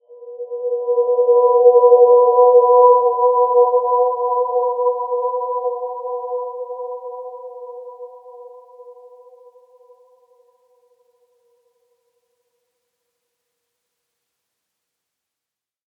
Dreamy-Fifths-B4-mf.wav